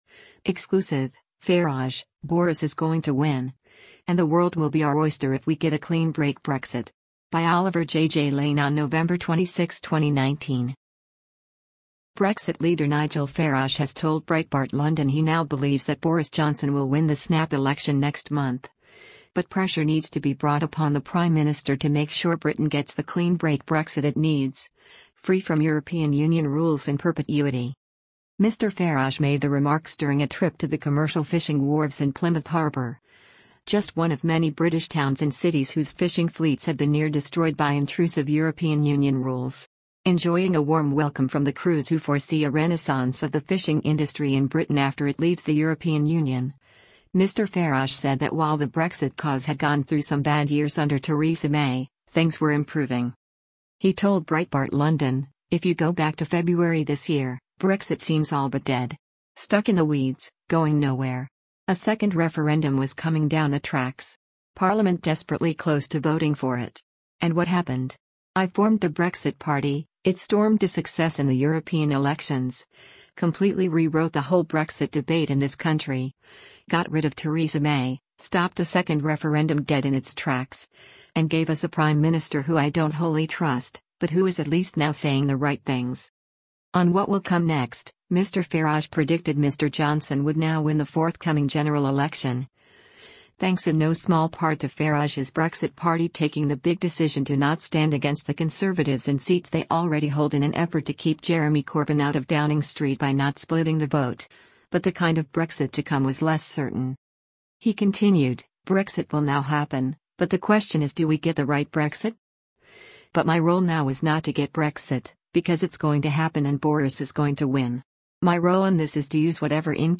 Mr Farage made the remarks during a trip to the commercial fishing wharves in Plymouth harbour, just one of many British towns and cities whose fishing fleets have been near-destroyed by intrusive European Union rules.